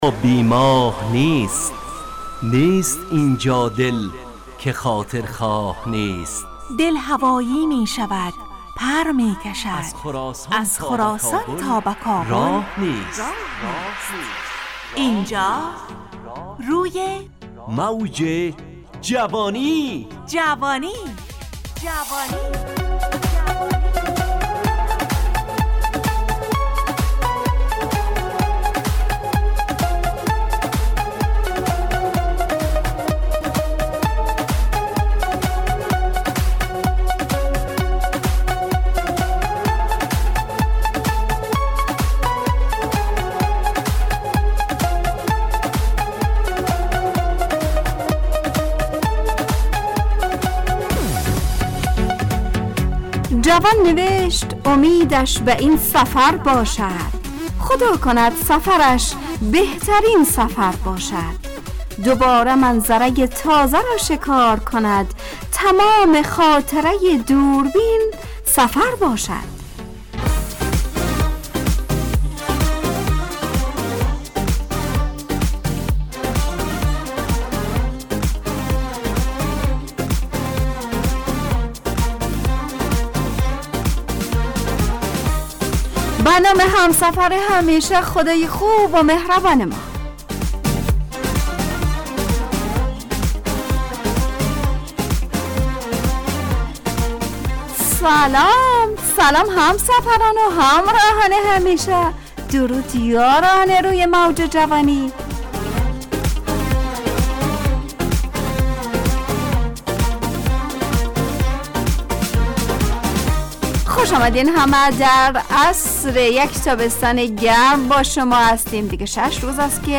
روی موج جوانی، برنامه شادو عصرانه رادیودری.
همراه با ترانه و موسیقی مدت برنامه 70 دقیقه . بحث محوری این هفته (سفر) تهیه کننده